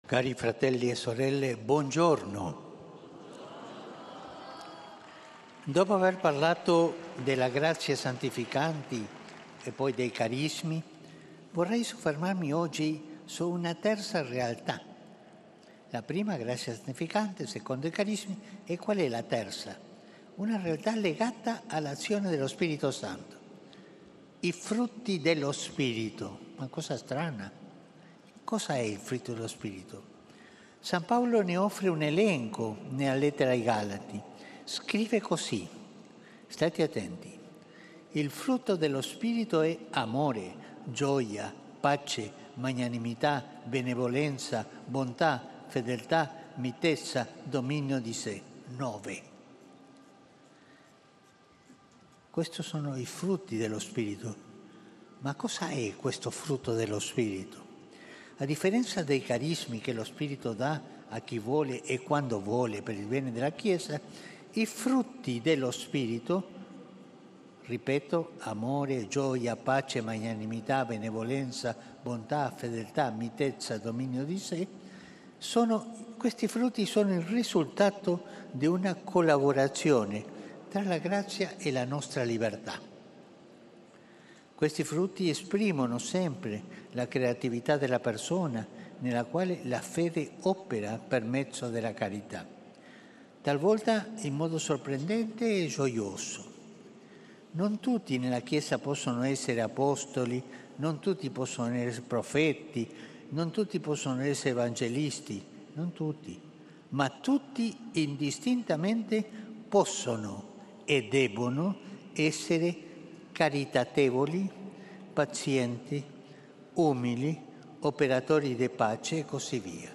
Piazza San Pietro Mercoledì, 27 novembre 2024
Ciclo di Catechesi.